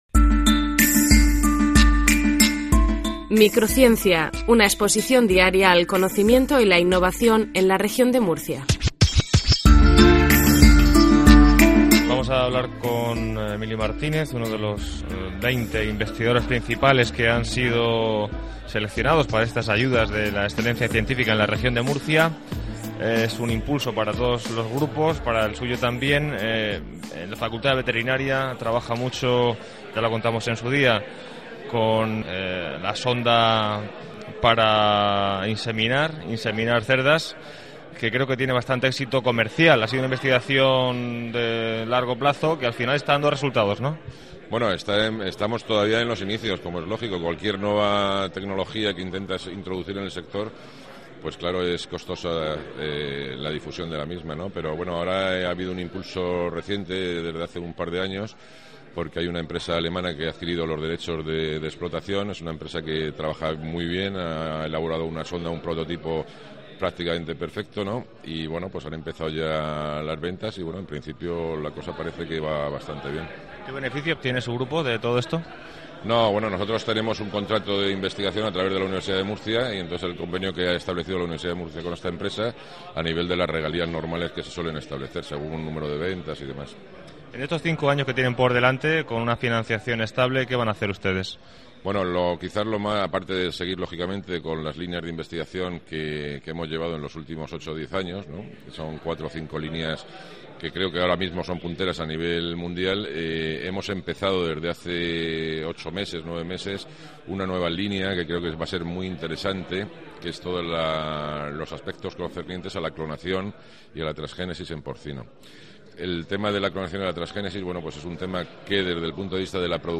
Dentro del Programa de Comunicación Pública de la Ciencia y la Tecnología, la Fundación Séneca patrocina el programa de radio "Microciencia", emitido a través de Onda Regional de Murcia.
Entrevista